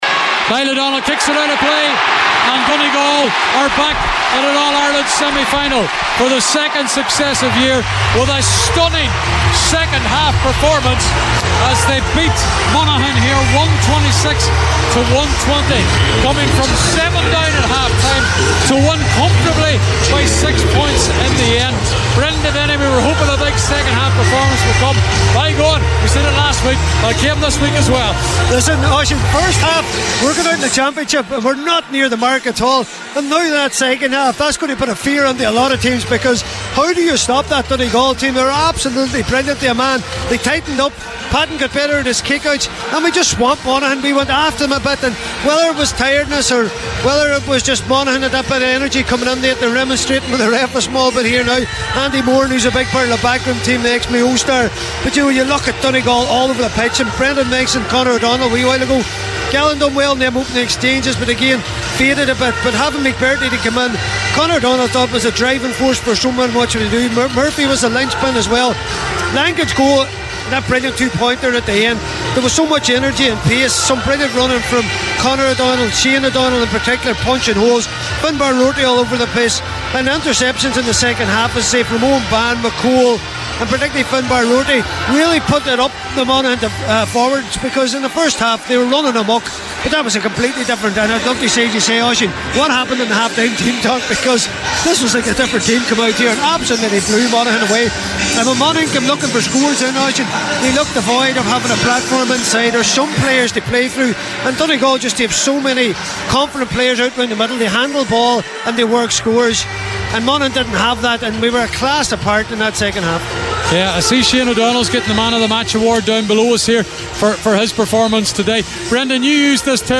Post-Match Reaction
were live at full time for Highland Radio Saturday Sport…